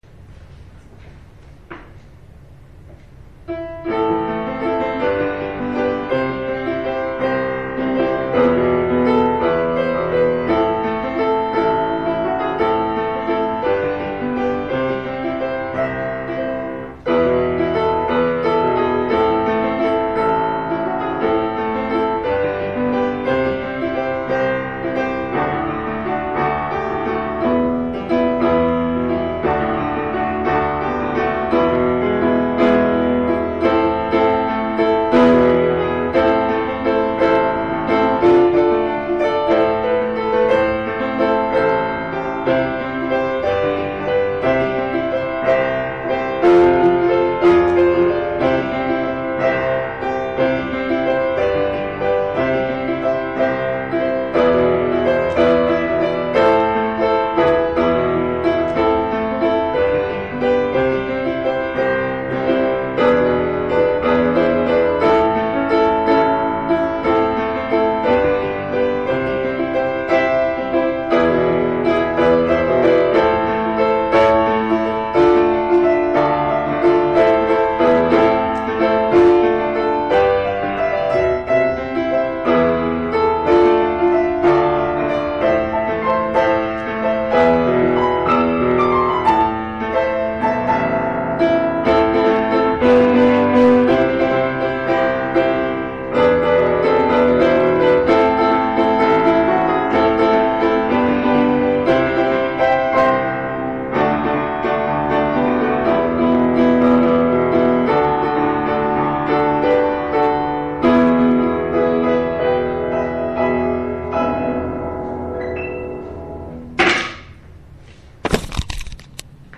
יש קובץ שהוא רק המנגינה.